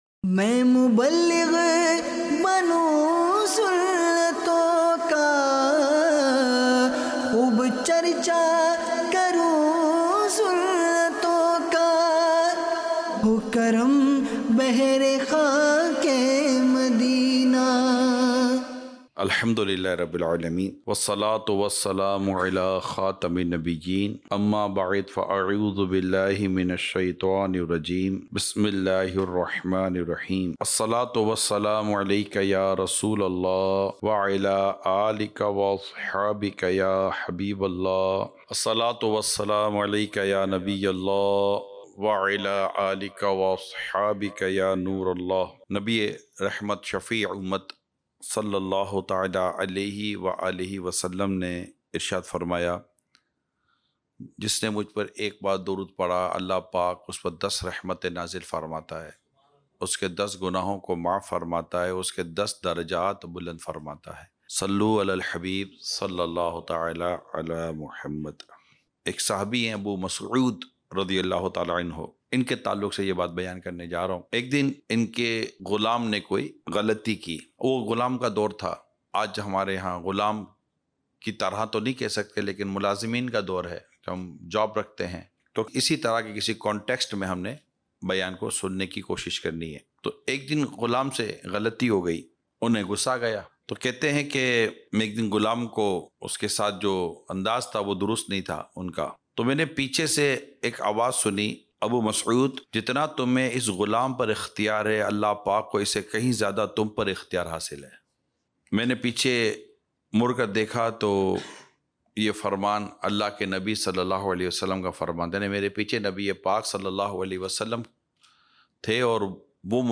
Sunnaton Bhara Bayan - Rasoolullah صلی اللہ علیہ وآلہ وسلم Ki Mubarak Seerat Par Amal Kijiye Dec 20, 2024 MP3 MP4 MP3 Share سنتوں بھرا بیان - رسول اللہ صلی اللہ علیہ وآلہ وسلم کی مبارک سیرت پر عمل کیجیئے